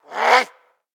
DayZ-Epoch/SQF/dayz_sfx/zombie/attack_3.ogg at 585f7879f327028b4461b42e614f71186bf97655